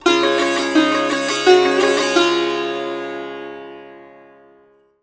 SITAR GRV 02.wav